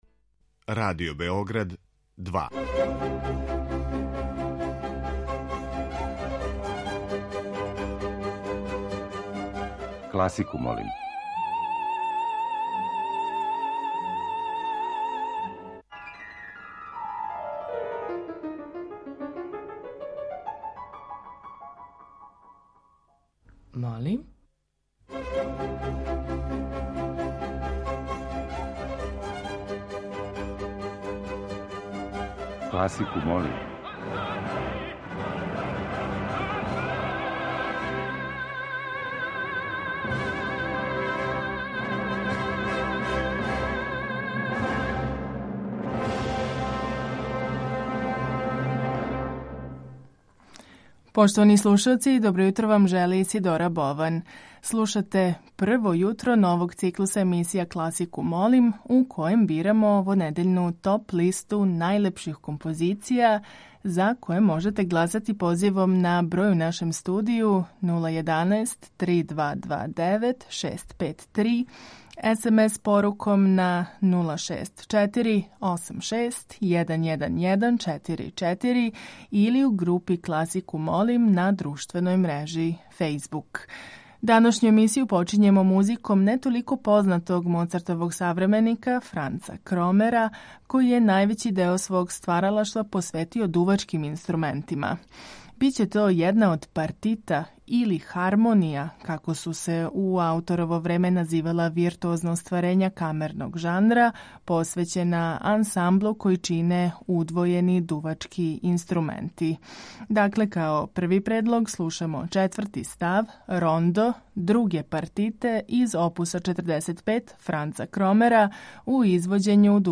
Тема циклуса носи наслов „Маршеви'.
klasika.mp3